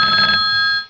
ring.au